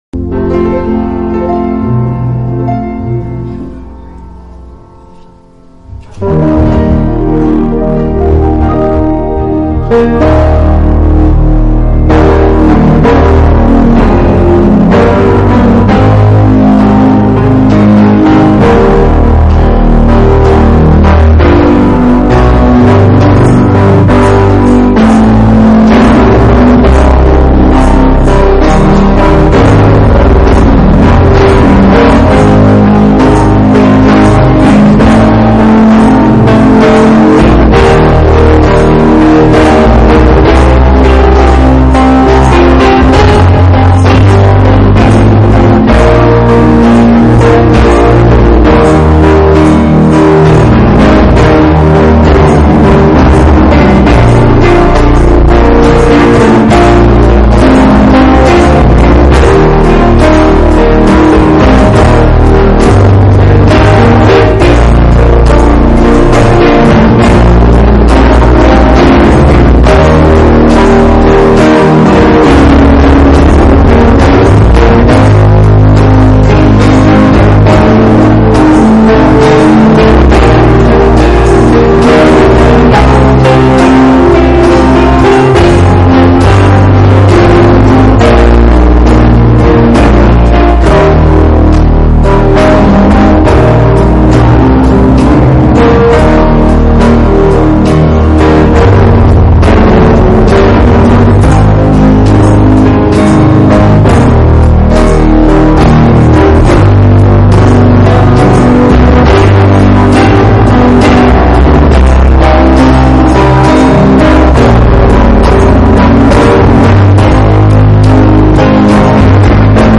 June 17, 2018 Father’s Day (Worship service/Memories) Preacher: Singers/Testimony Service Type: Sunday Evening Services Topics: Father's Day (Worship service/Memories) « “A Prayer For Guidance” Giving Honor To The Builder »